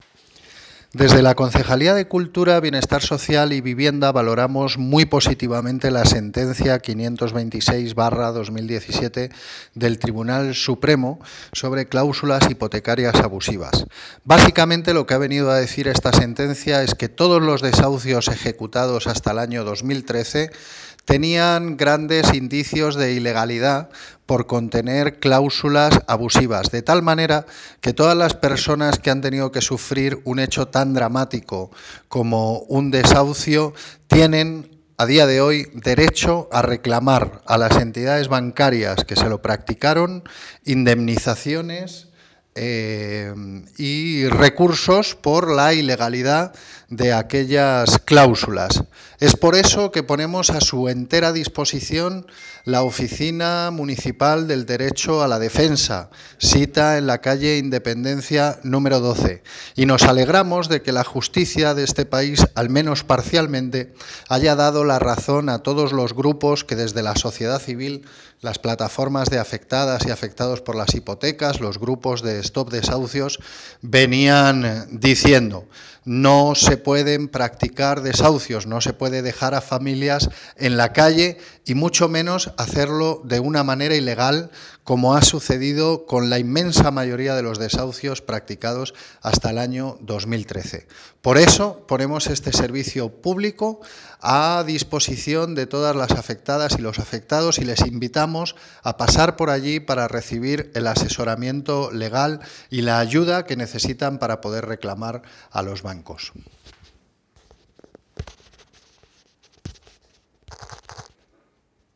Audio - Gabriel Ortega (Concejal de Cultura y Bienestar Social) Sobre Oficina vivienda